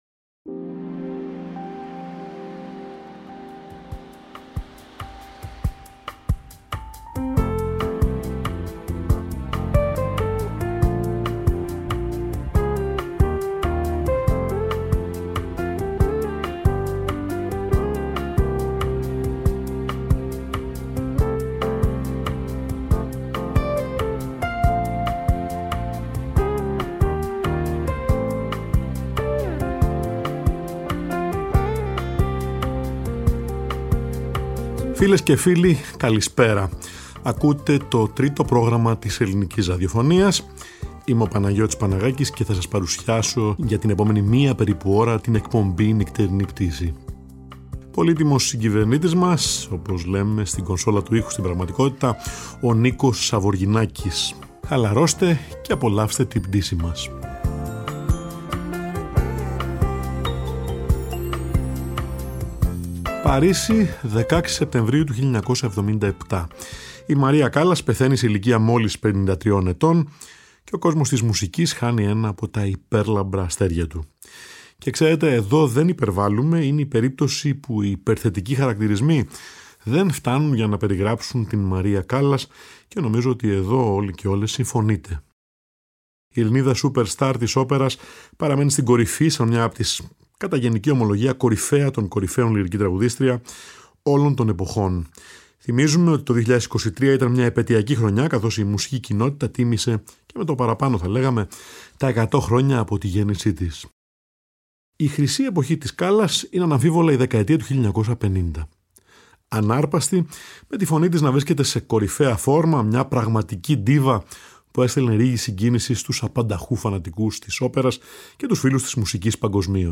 Κλασικη Μουσικη
Μουσικη Jazz